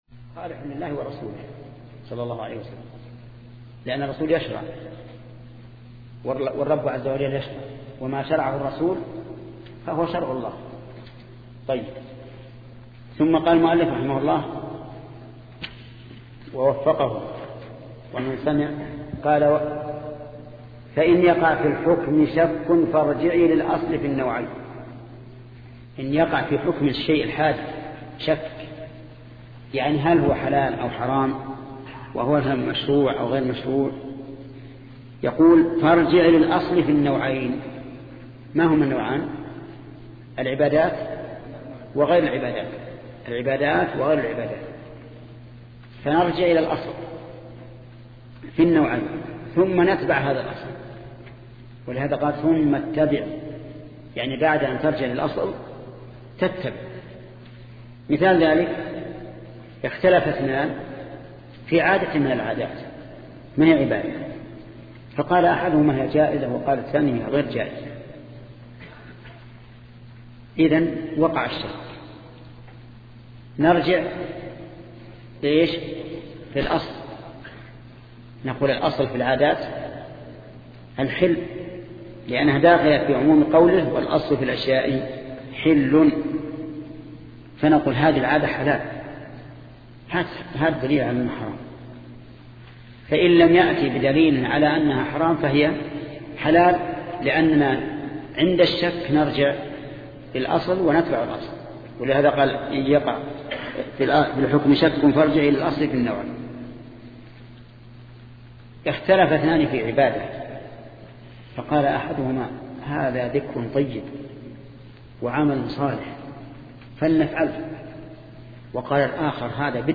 شبكة المعرفة الإسلامية | الدروس | شرح المنظومة في أصول الفقه 4 |محمد بن صالح العثيمين